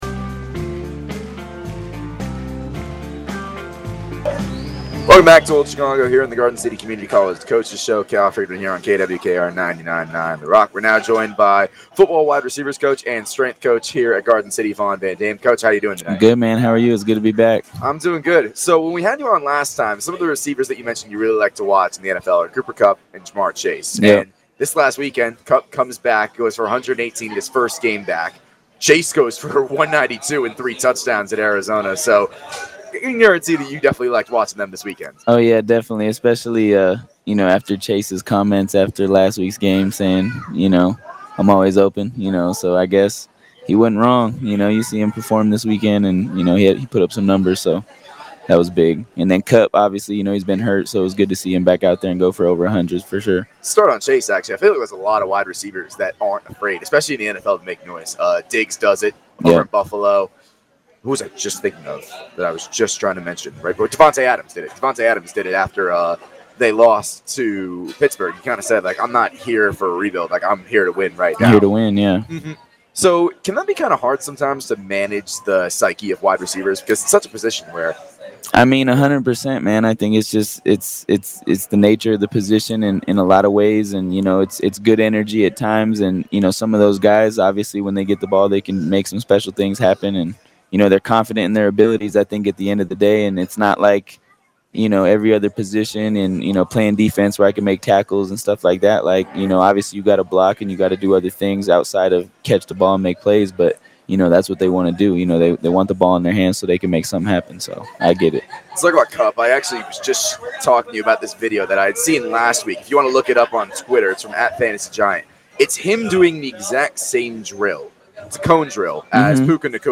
GARDEN CITY, KS. – The Garden City Community College Coach’s Show returned at Old Chicago Pizza & Taproom on Thursday.